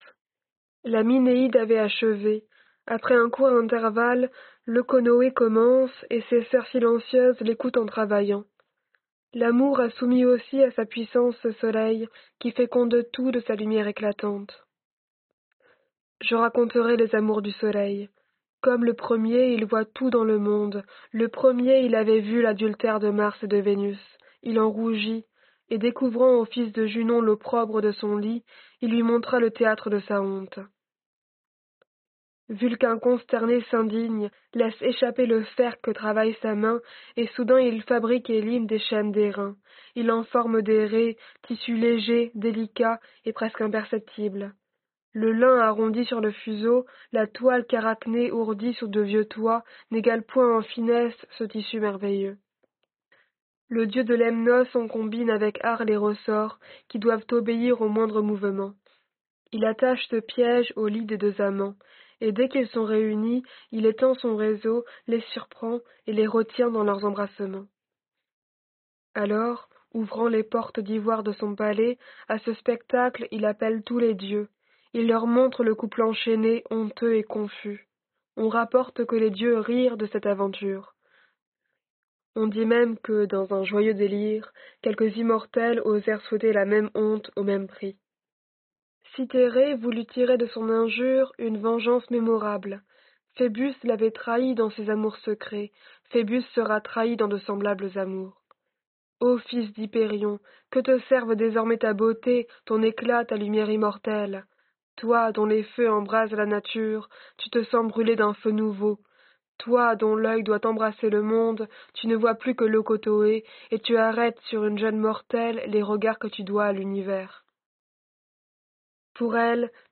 Lecture des métamorphoses de Clytie et de Leucothoé · GPC Groupe 1